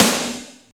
45.04 SNR.wav